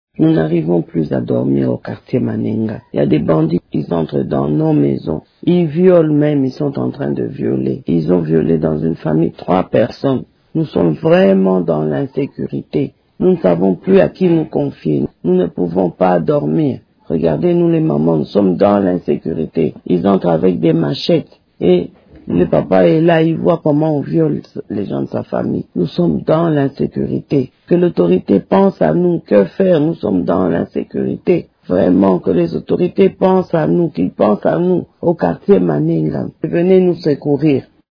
Suivez ce témoignage d'un habitant: